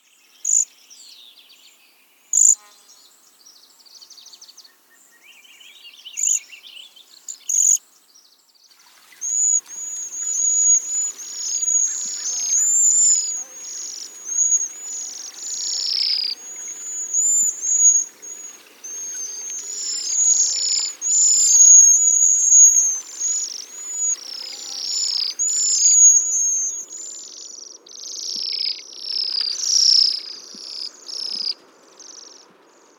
Звуки свиристелей
На этой странице собраны записи пения свиристелей – птиц с удивительно нежным и мелодичным голосом.